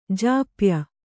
♪ jāpya